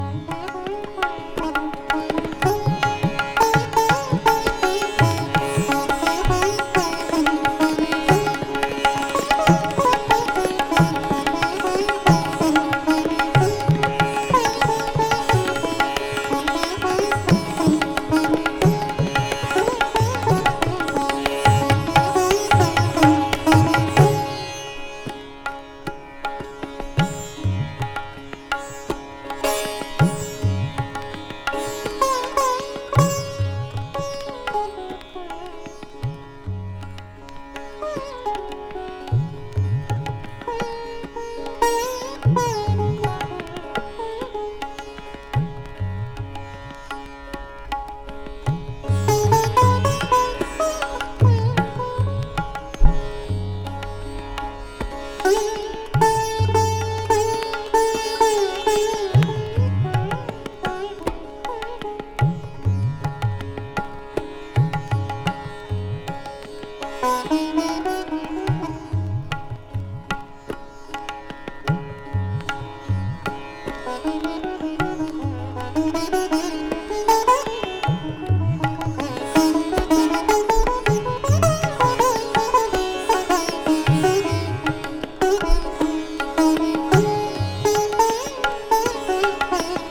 シタールを用いた典型的なインド古典音楽ながら、人間的な親しみさえ漂わせる癒しの世界を展開。